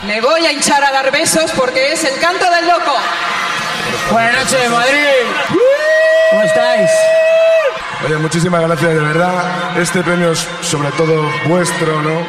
Transmissió des del Palacio de Deportes de la Comunidad de Madrid.
La cantant Alaska (Olvido Gara ) lliura el premi a la millor gira al grup El Canto del Loco (Zapatillas 2006 Tour ).